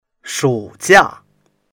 shu3jia4.mp3